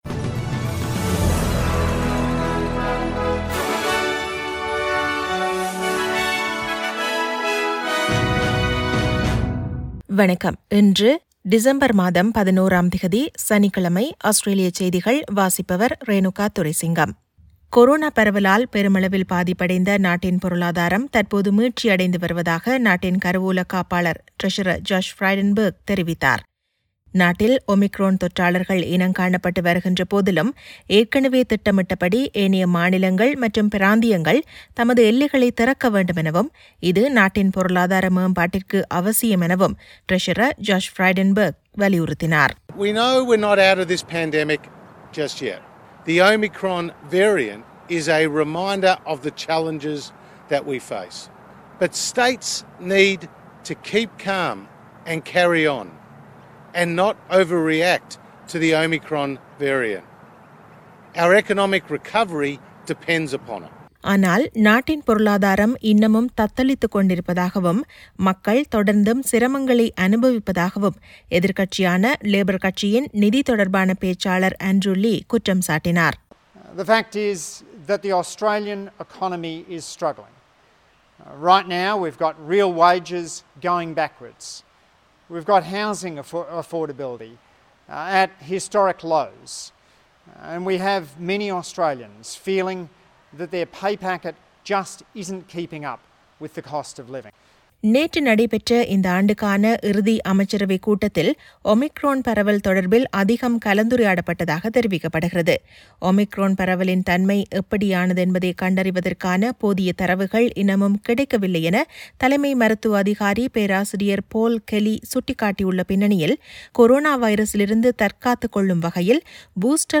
Australian news bulletin for Saturday 11 December 2021.